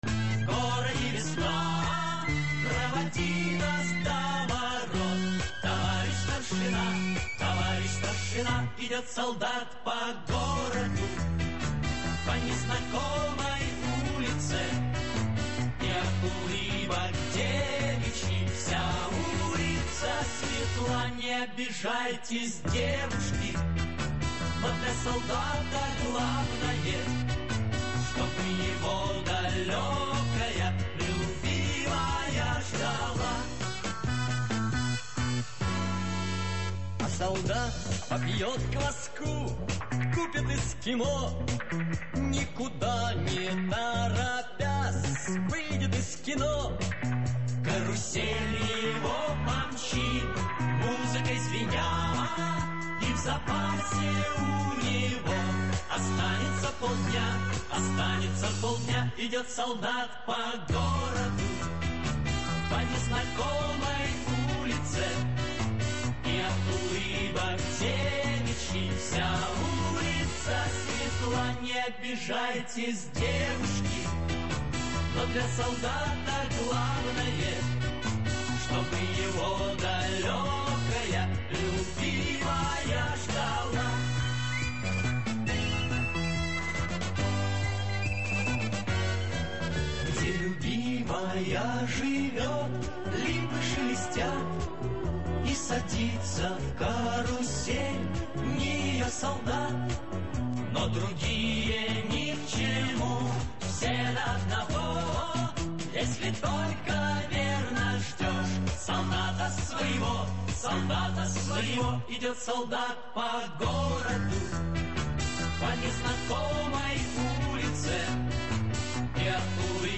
P.S. За это время на сайте появилась песенка со сборов - она